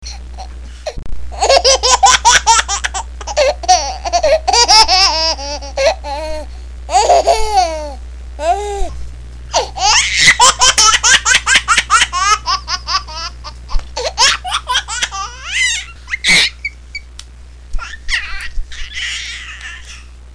Funny Twin babies Laughing ringtone free download